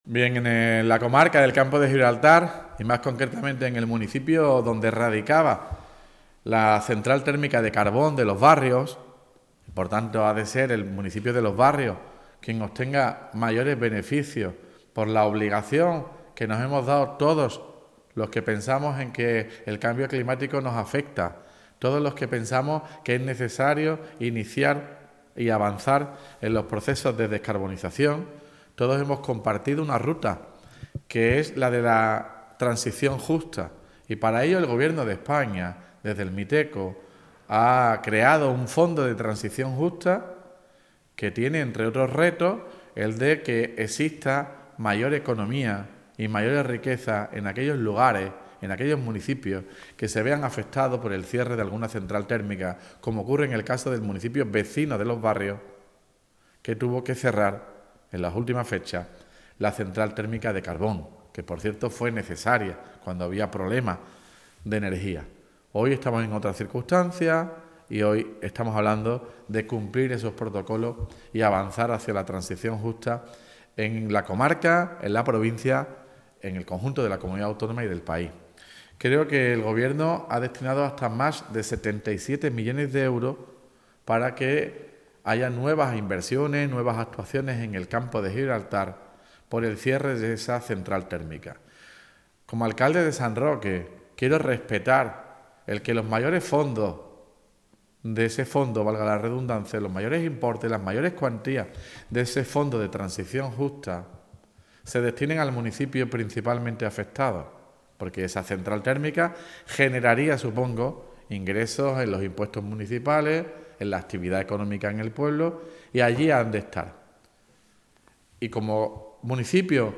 TOTAL_ALCALDE_FONDO_TRANSICIÓN_JUSTA.mp3